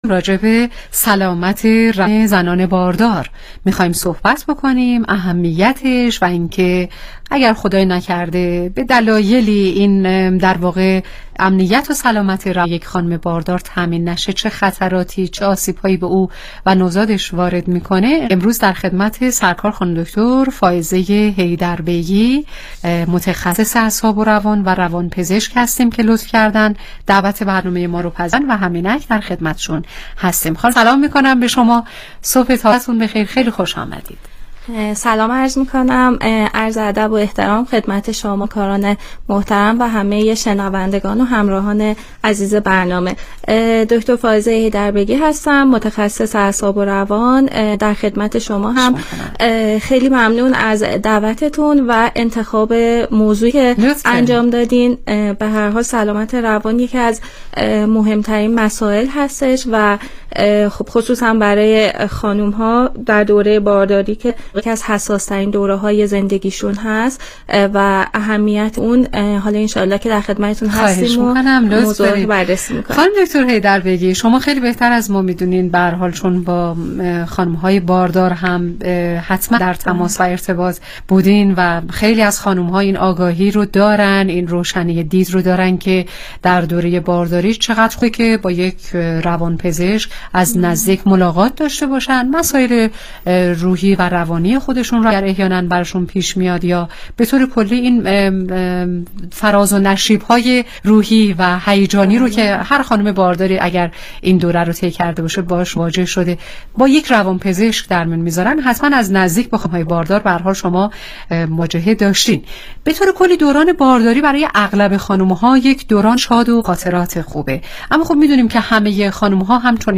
برنامه رادیویی
متخصص روانپزشکی